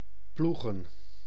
Ääntäminen
IPA: /la.bu.ʁe/